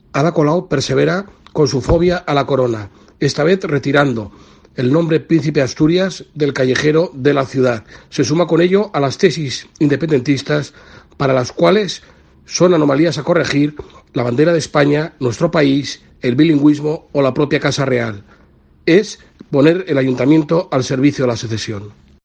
Alberto Fernández Díaz, presidente municipal del PP en el Ayuntamiento.